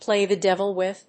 アクセントpláy the dévil with…